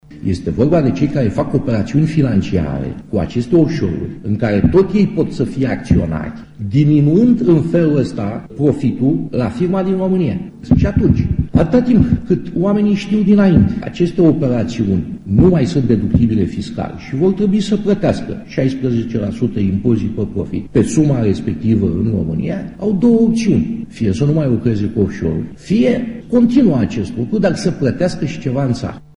El spune că autorităţile trebuie să ia măsuri pentru a-i descuraja pe oamenii de afaceri care fac astfel de operaţiuni financiare: